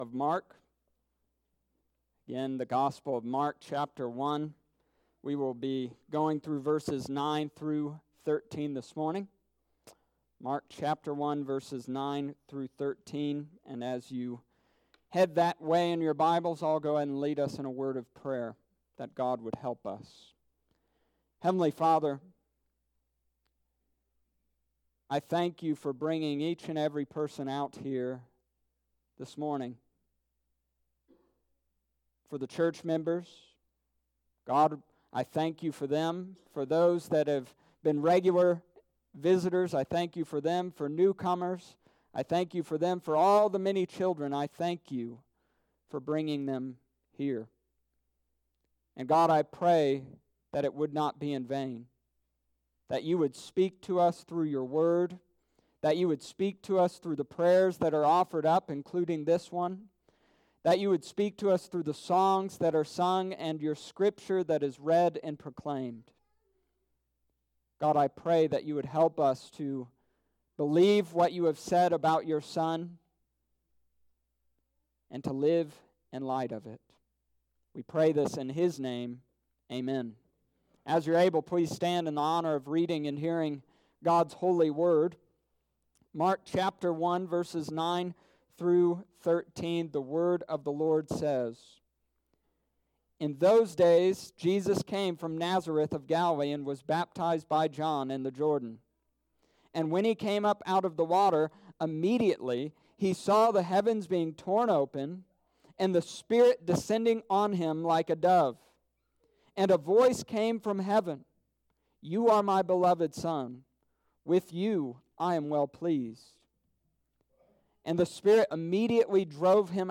Summary of Sermon: This week we discussed the baptism and temptation of Jesus.